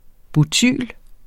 Udtale [ buˈtyˀl ]